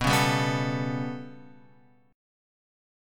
B 7th Suspended 2nd Sharp 5th